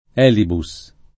[ $ libu S ]